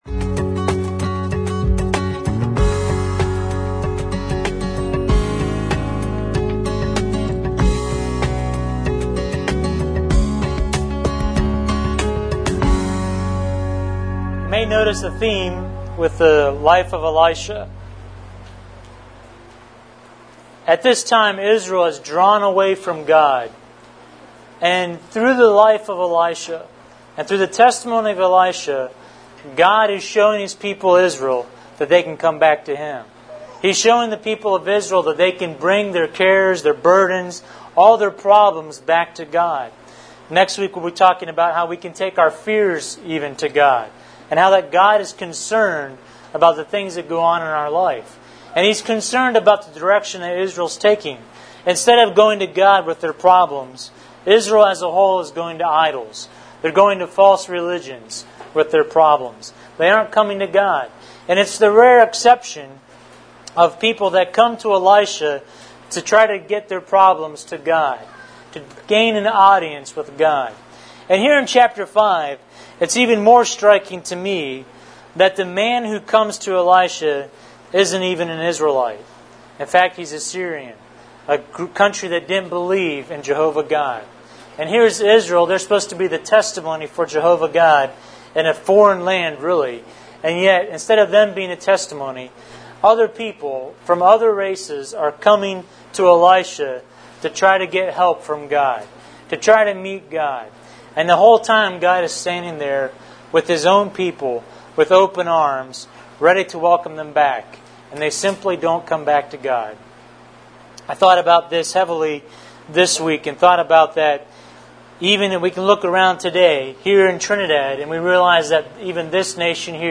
Here you will find audio from the various services.